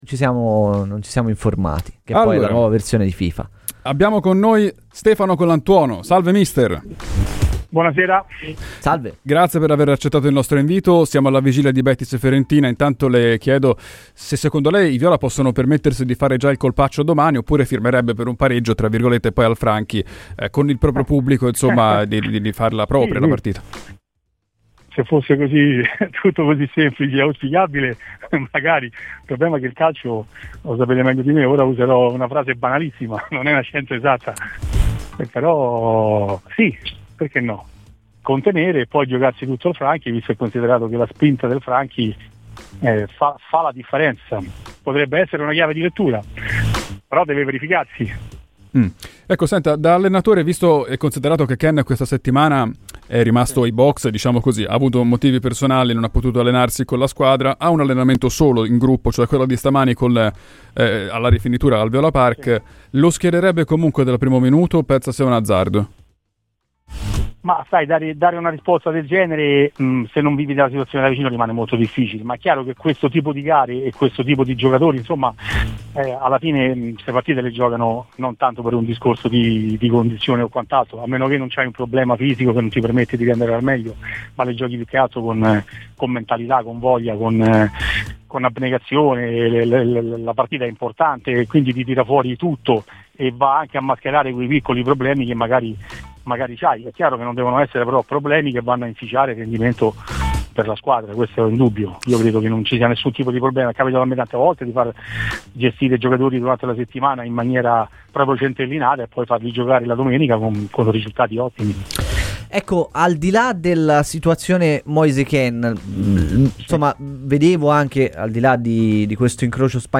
Il tecnico Stefano Colantuono è intervenuto a Radio FirenzeViola durante "I Tempi Supplementari". Queste le sue parole sulla gara di domani tra Betis e Fiorentina: "L'idea della Fiorentina potrebbe essere anche quella di contenere nella gara di andata, mentre al ritorno potrebbe sfruttare il fattore campo.